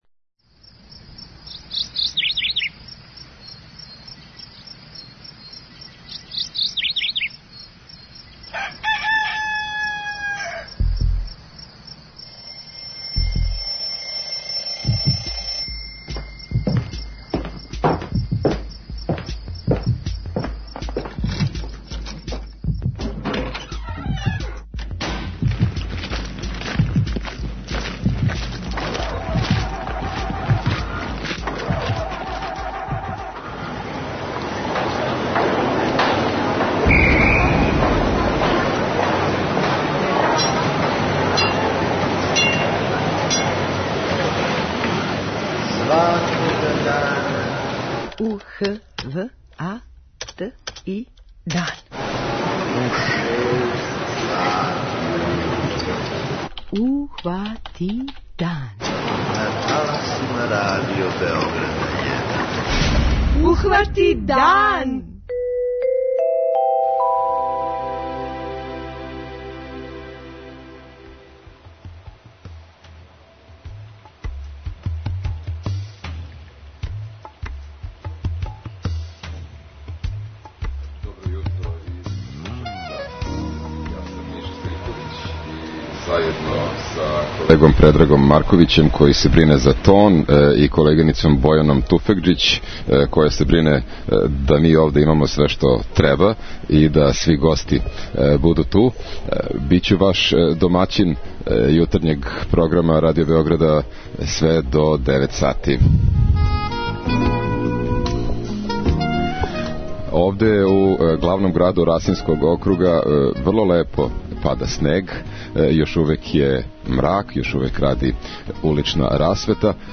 Ухвати дан - уживо из Крушевца
Радио Београд у Kрушевцу! Хватамо дан уживо у старој престоници Србије! У граду који нам је дао Чкаљу, Ђузу, Љубинку Бобић и Радмилу Савићевић, с бројним гостима разговарамо о култури, историји, винима из Жупе, али и о баксузима, угурсузима и намћорима.